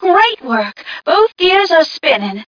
1 channel
mission_voice_t9ca018.mp3